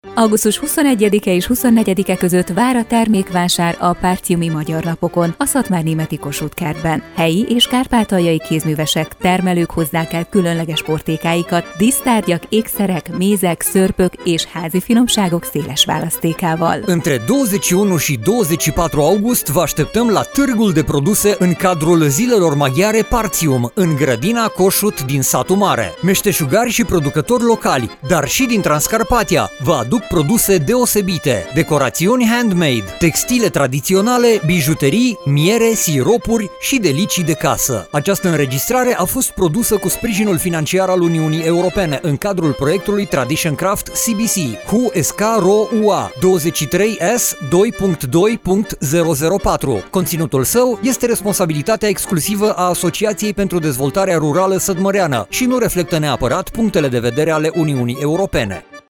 Radio spot - Târg de "Zilele Partium" - "Partiumi Magyar Napok" Termékvásár